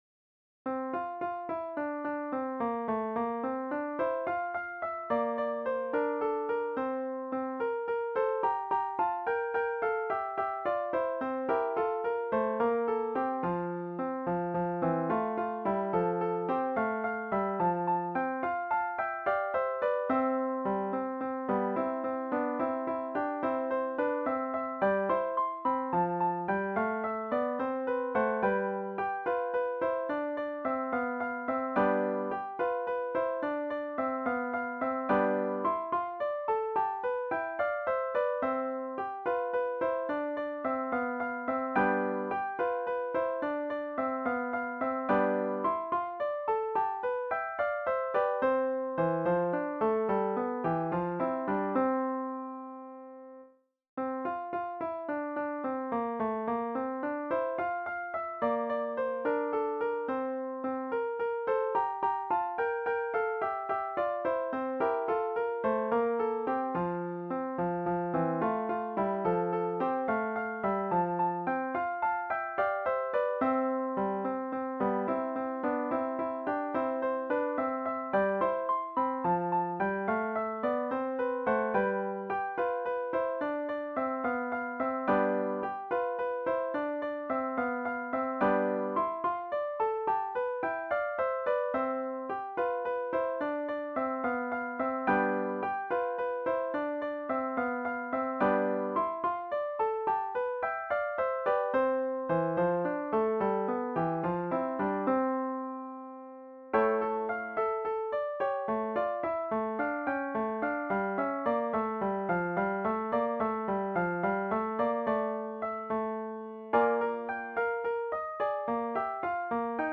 written for two guitars